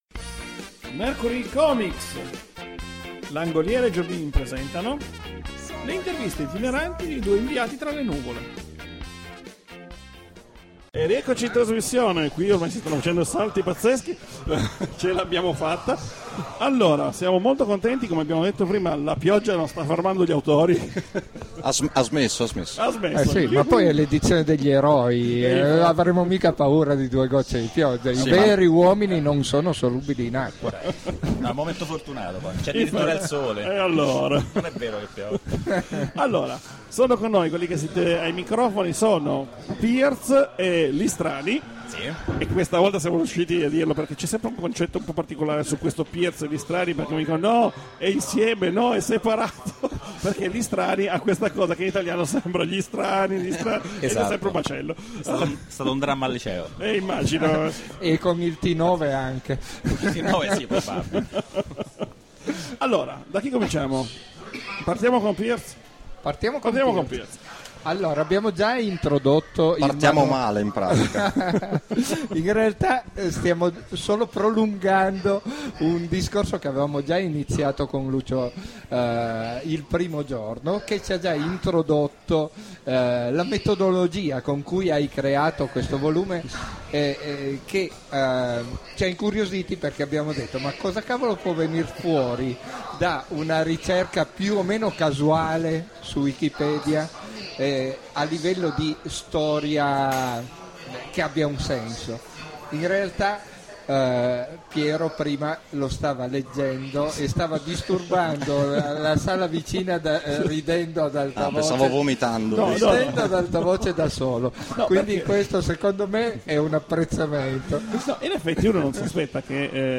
Potete sentire tutte le interviste della giornata QUI, se invece volete sentire solo l’intervista senza canzoni la trovate sotto, cliccate su Play e si parte!